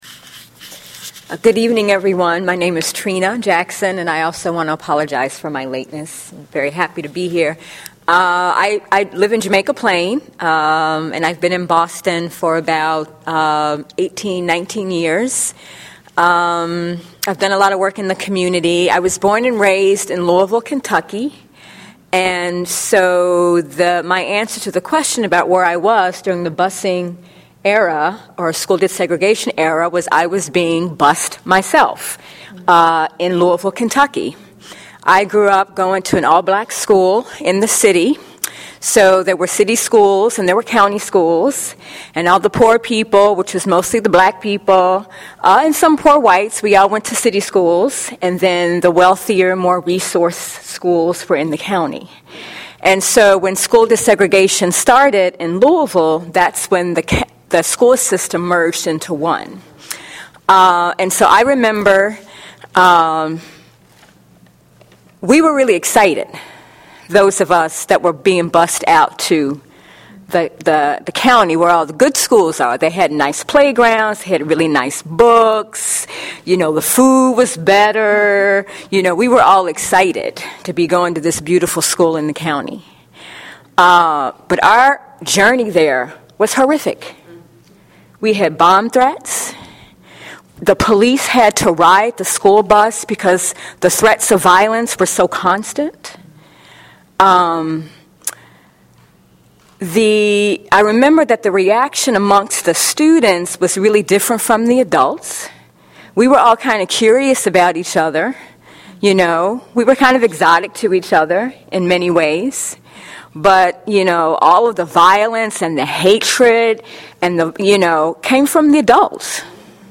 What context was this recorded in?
Voices from the Brighton Allston Congregational Church Story Circle